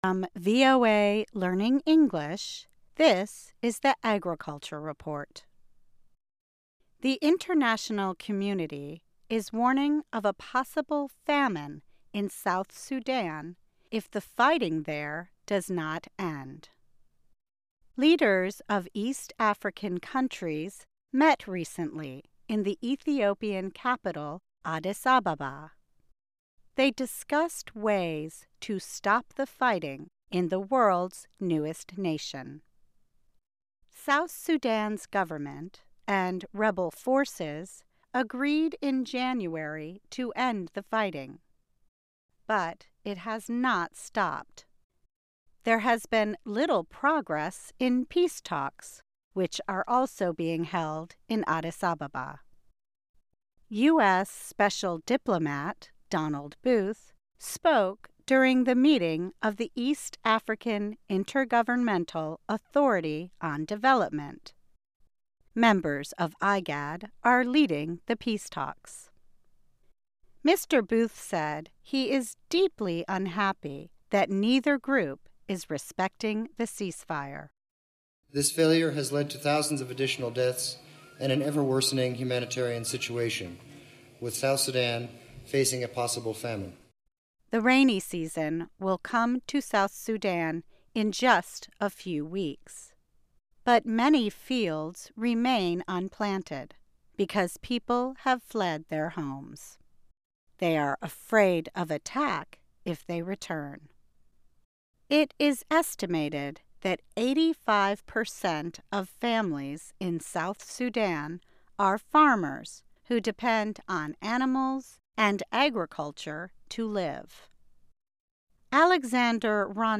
Learn English as you read and listen to a weekly show about farming, food security in the developing world, agronomy, gardening and other subjects. Our stories are written at the intermediate and upper-beginner level and are read one-third slower than regular VOA English.